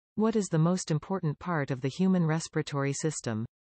You will hear a question.